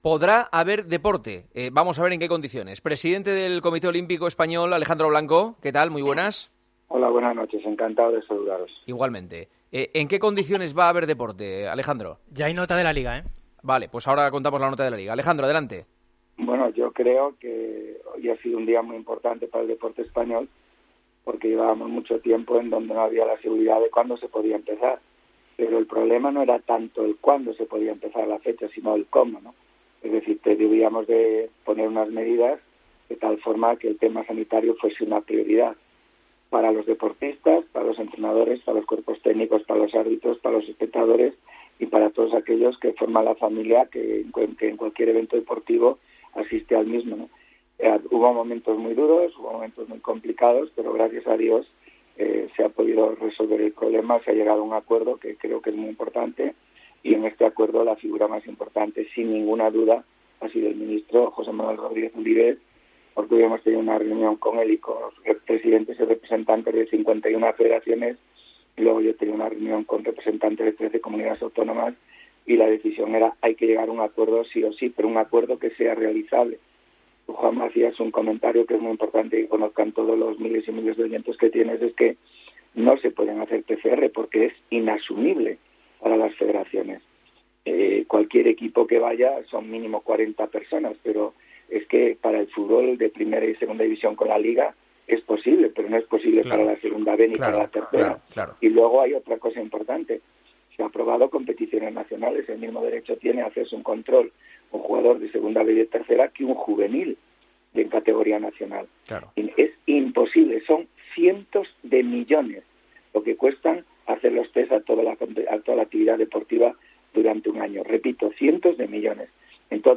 Entrevista en El Partidazo